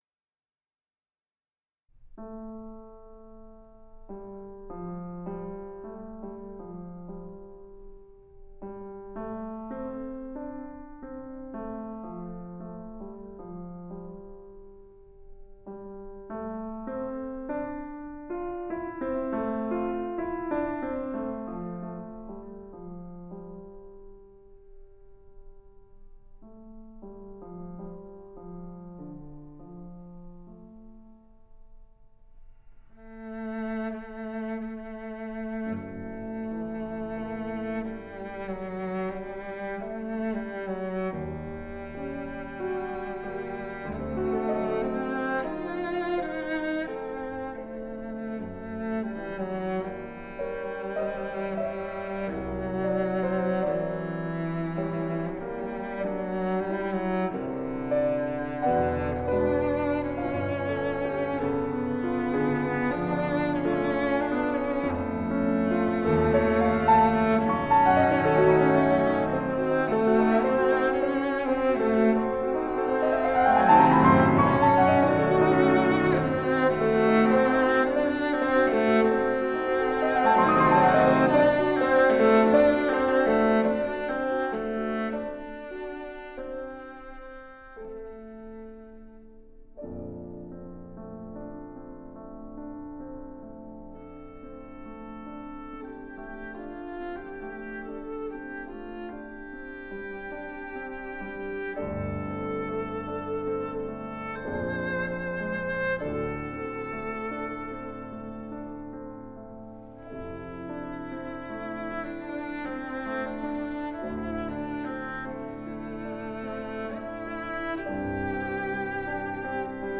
★ 於加拿大魁北克Domaine Forget音樂廳錄製！